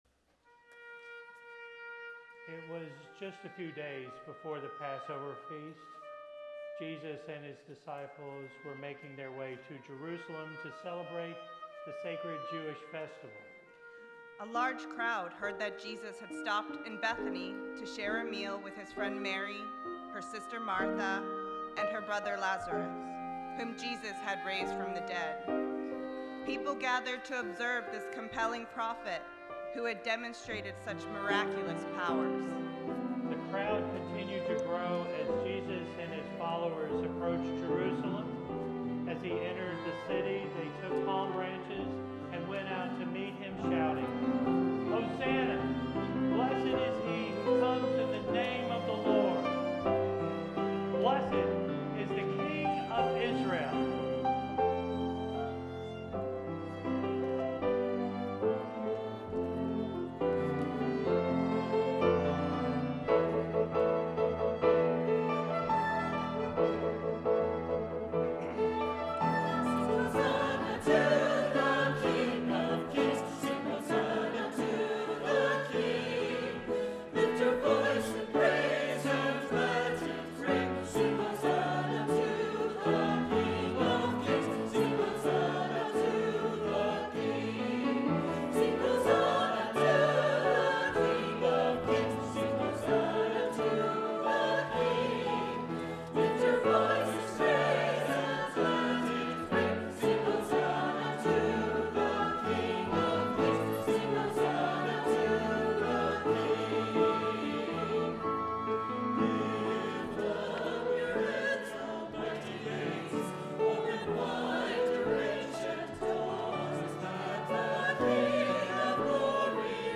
Palm Sunday - Easter Cantata